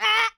Player Boulder Push Sound Effect
player-boulder-push.mp3